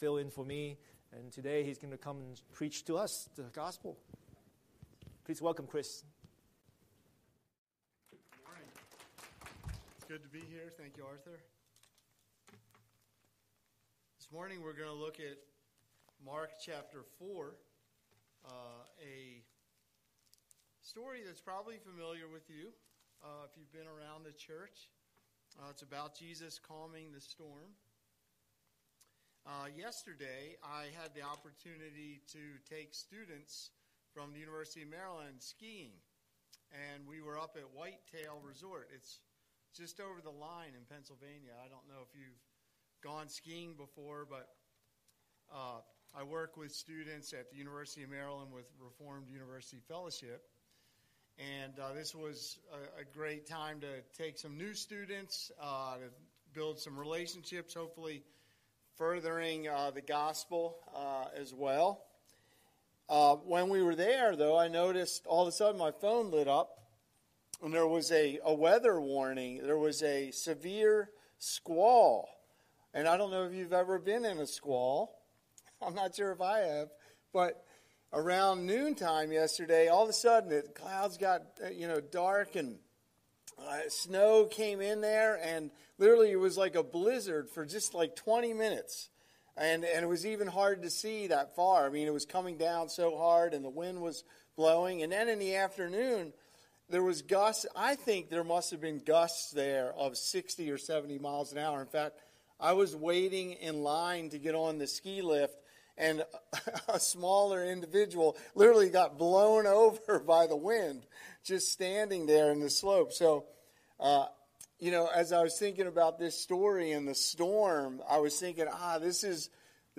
Scripture: Mark 4:35–41 Series: Sunday Sermon